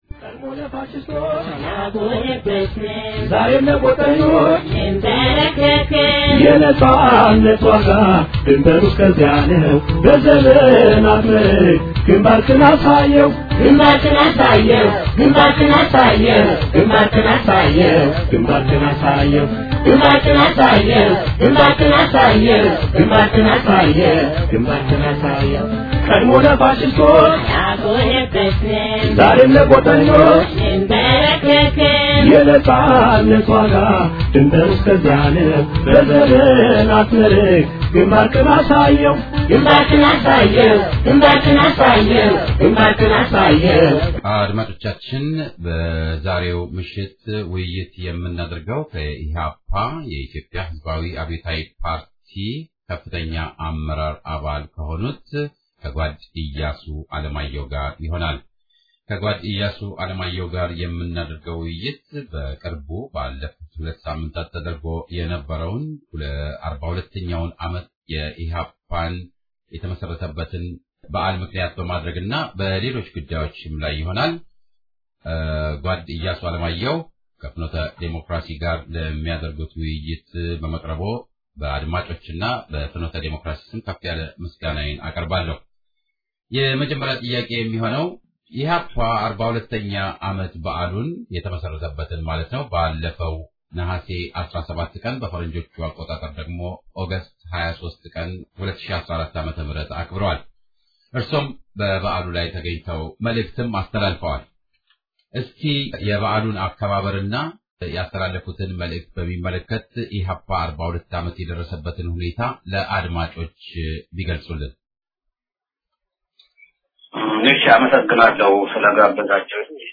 Finote Democracy Voice of Ethiopian Unity Radio - EPRP Metro Washington DC Weekly Radio Broadcast